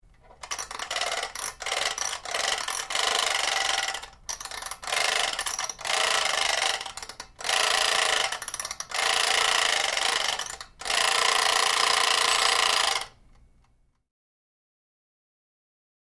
Clock Alarm Old; Windup